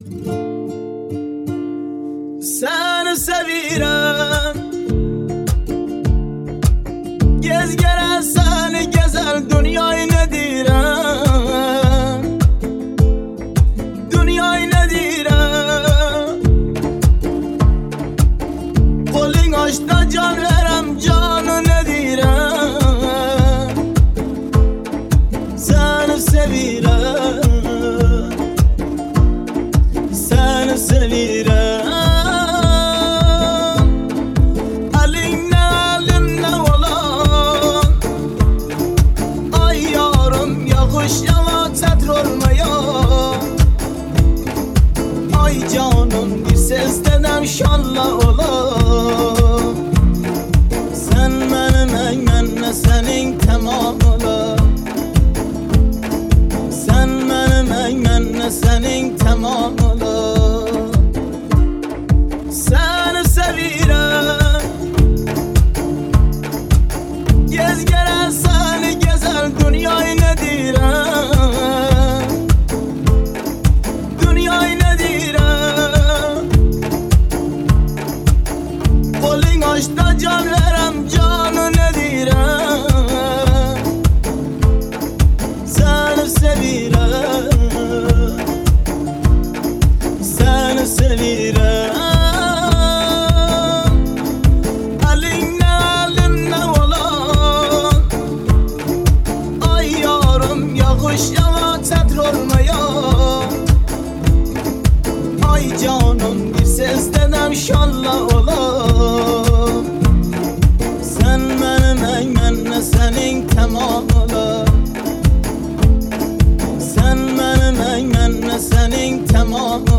آهنگ ترکی